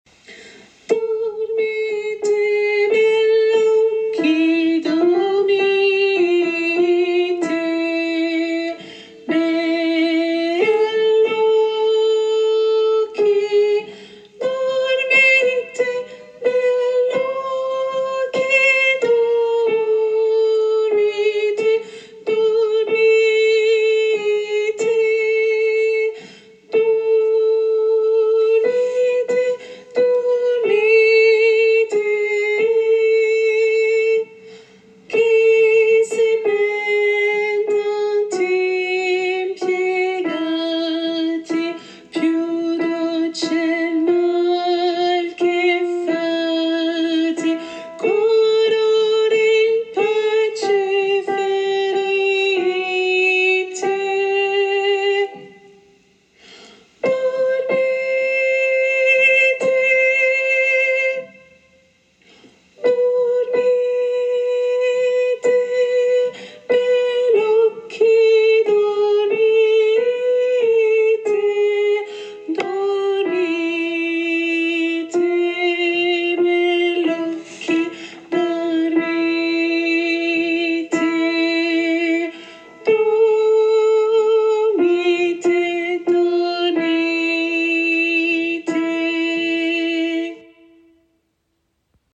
Mezzo-soprano et autres voix en arrière